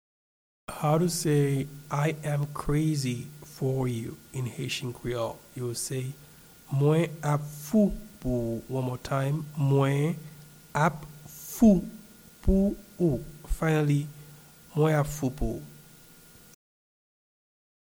Pronunciation and Transcript:
I-am-crazy-for-you-in-Haitian-Creole-Mwen-ap-fou-pou-ou-pronunciation.mp3